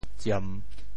潮州 ziem3 文 对应普通话: zhàn ①据有，用强力取得：~领|攻~敌军据点。